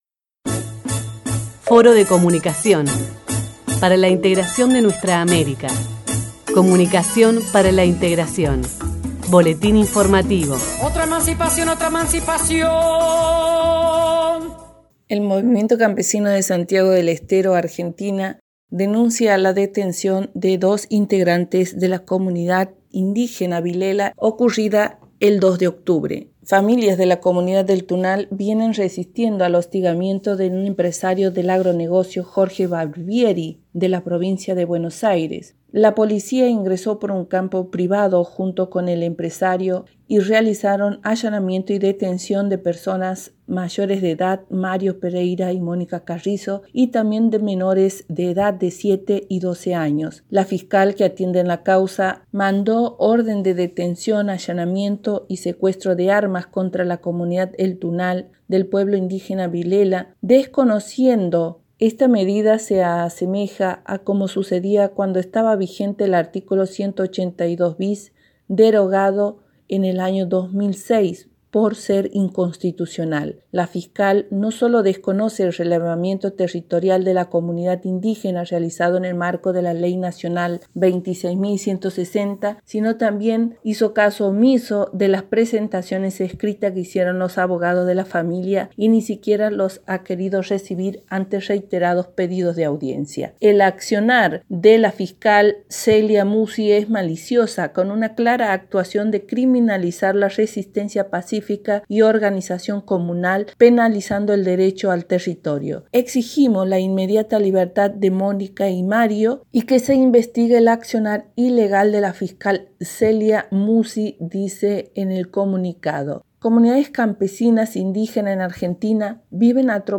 reporte cloc comunicacion Argentina.mp3